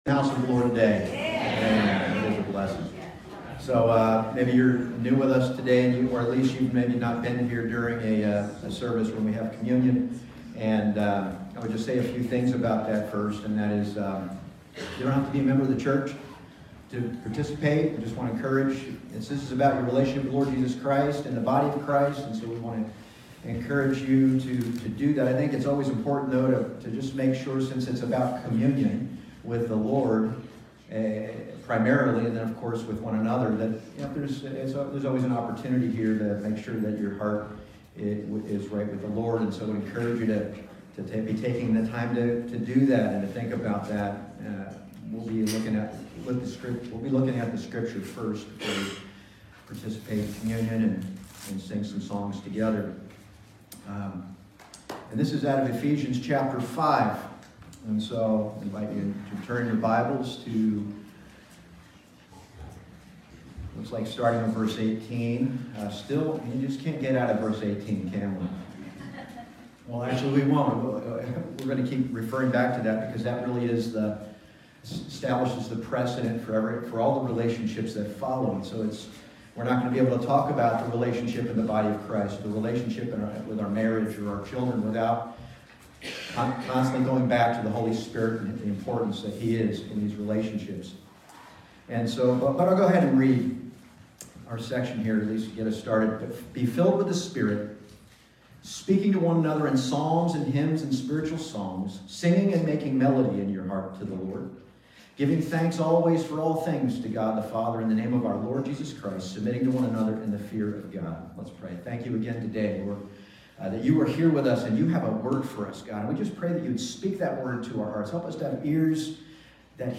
Ephesians Passage: Ephesians 5:18-21 Service Type: Sunday Morning « Under the Influence of Another Power The Spirit-Filled Marriage